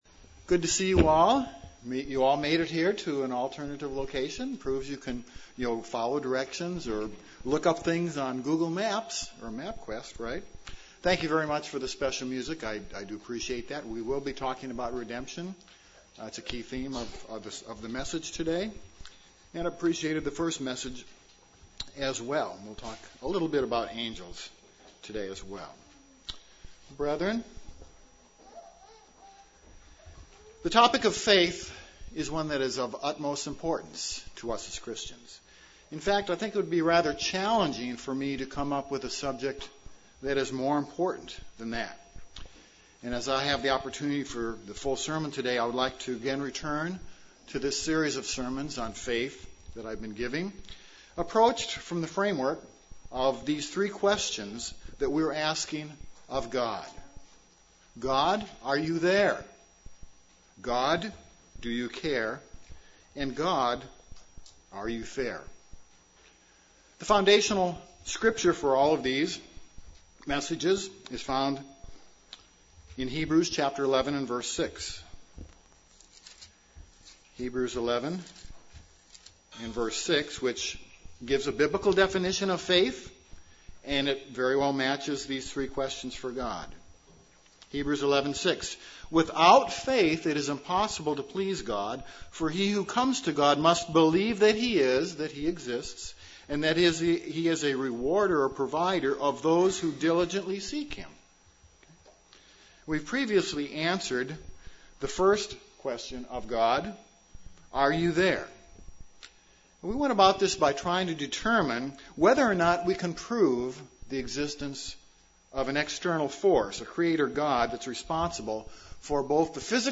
The three questions we ask of God: Are You there? Do You care? Are You fair? This sermon continues the discussion of how God cares for us by providing for our spiritual needs.